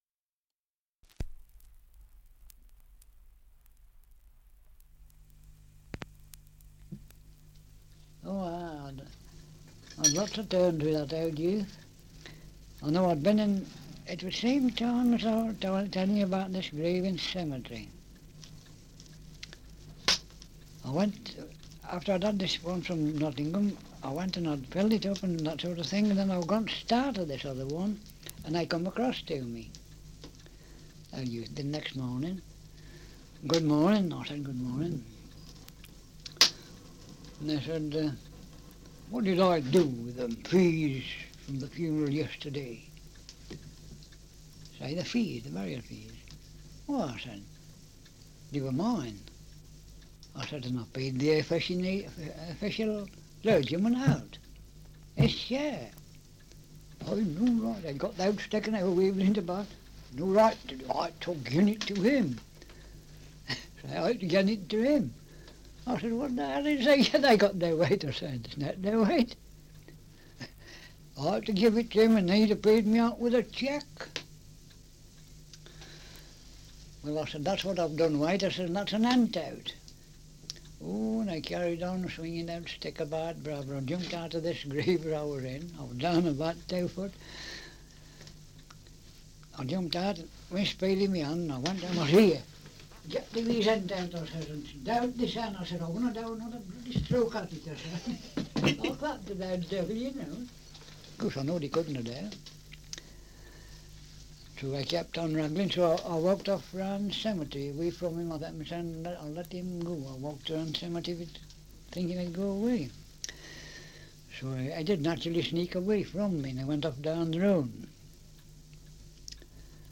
Survey of English Dialects recording in Alton, Staffordshire
78 r.p.m., cellulose nitrate on aluminium